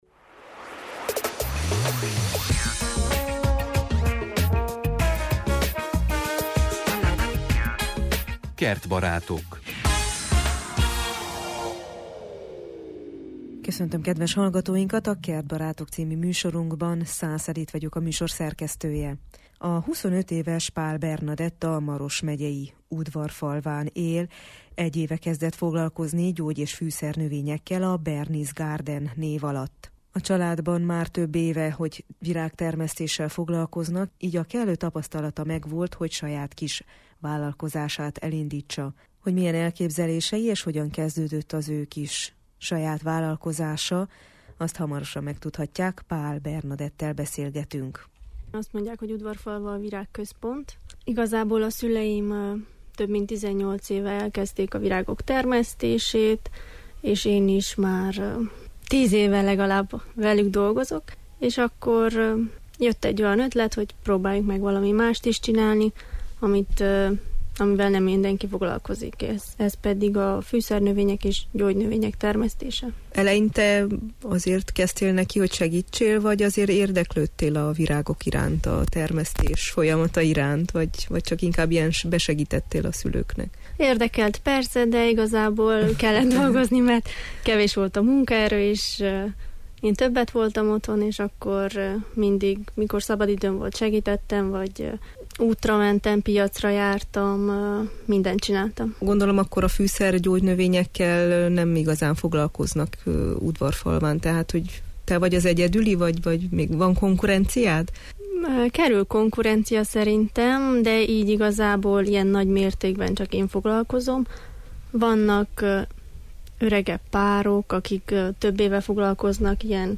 A vásárlók kedvence a citrom illatú kakukkfű, de több féle bazsalikom, menta, oregáno, majoránna, rozmaring, csípős paprika, zsálya, tárkony, levendula, citromfű, petrezselyem is megtalálható kínálatában. A beszélgetést az alábbi linkre kattintva hallgathatják meg.